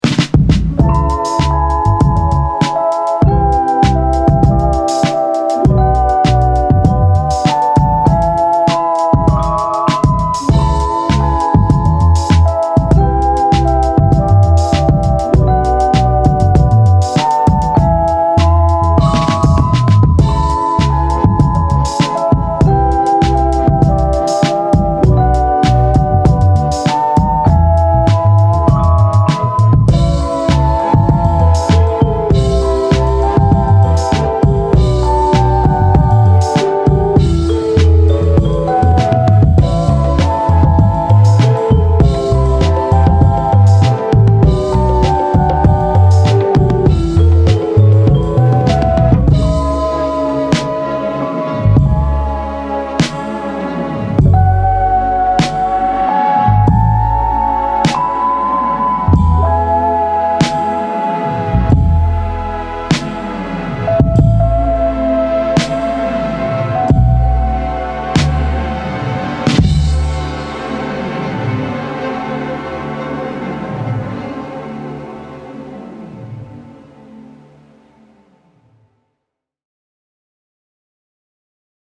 Teletone Audio Vespertone 是一个虚拟乐器插件，它可以让你使用 Wurlitzer, Vibraphone, Celeste 和 Rhodes 四种声学乐器的原始声音，并将它们变形为抽象的版本。